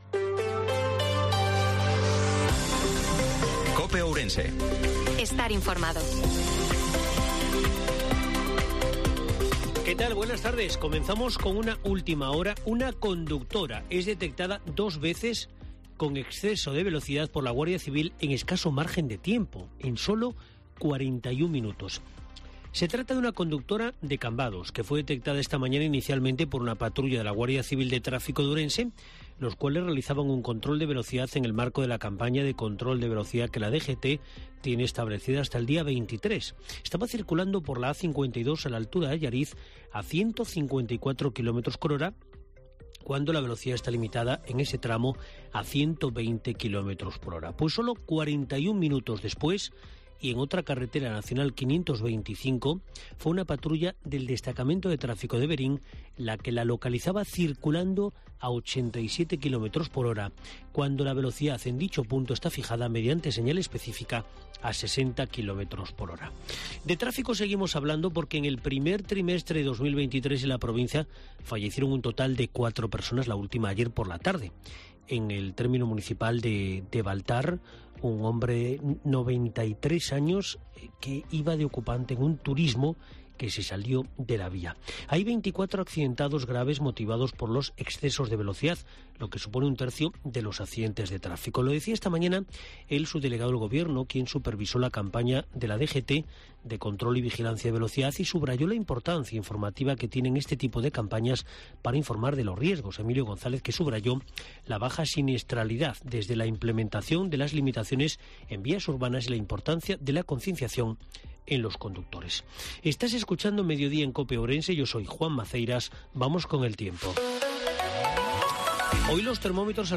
INFORMATIVO MEDIODIA COPE OURENSE-19/04/2023